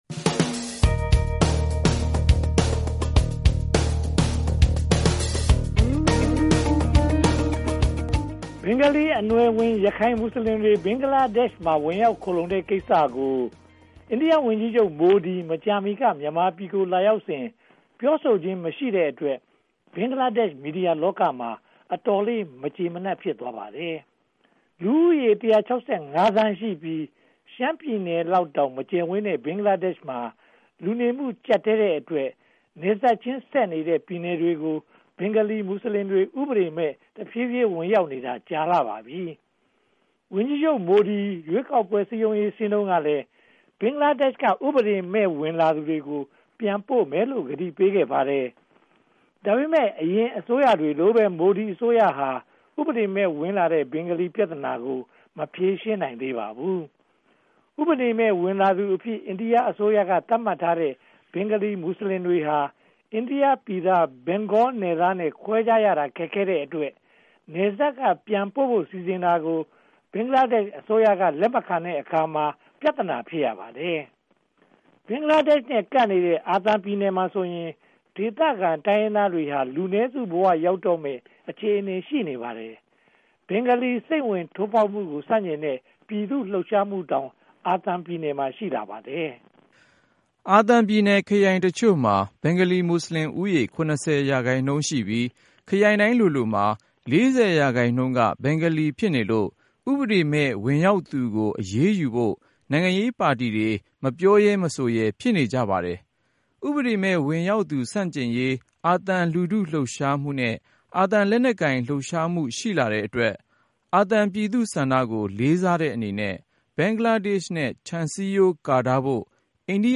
သတင်းသုံးသပ်ချက်